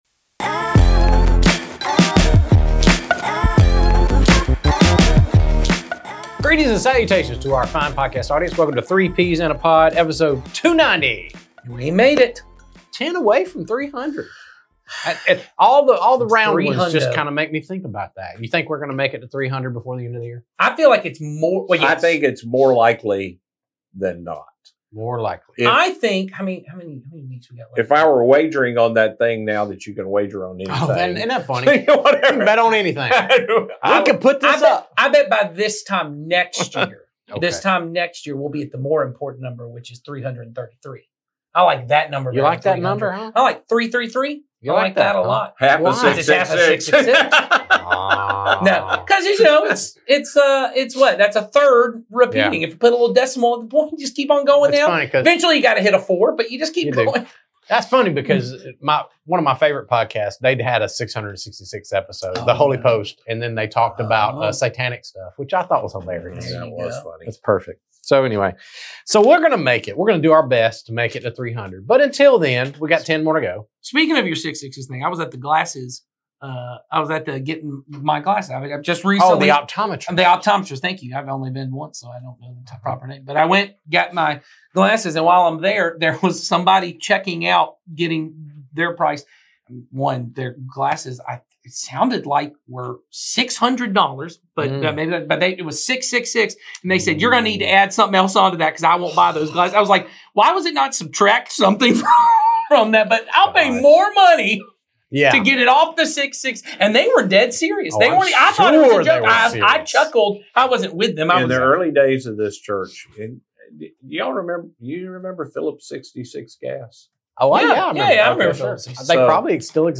A Conversation On Evangelism vs Discipleship | 3Ps in a Pod Episode 290